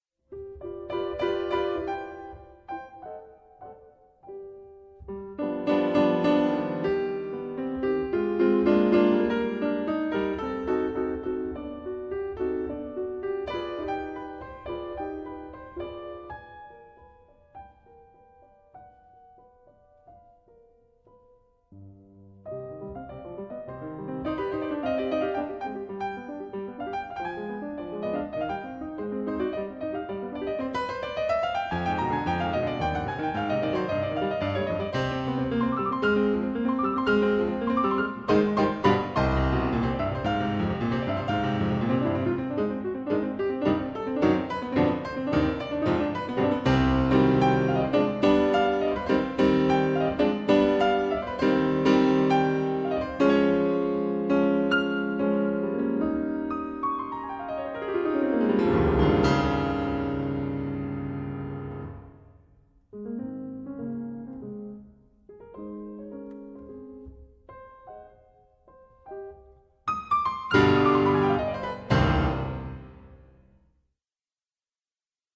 This is no ordinary recorded recital.